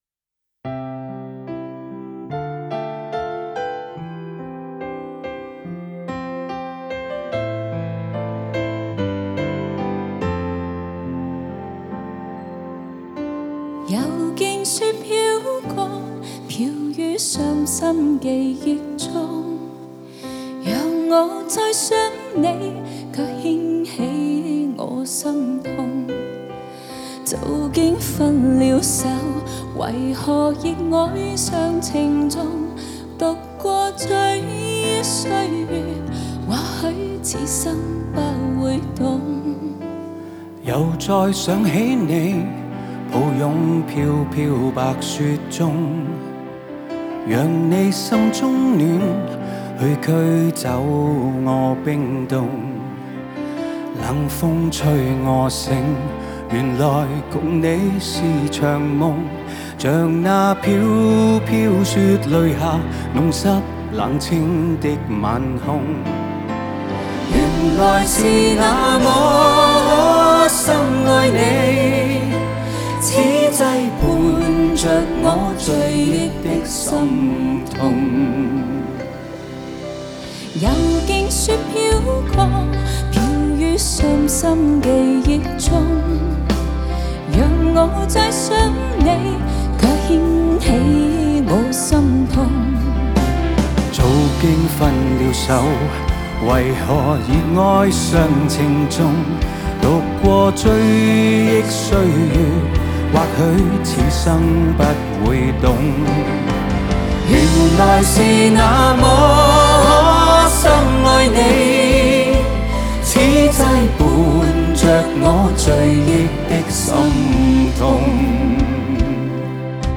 键盘
吉他
贝斯
鼓
和音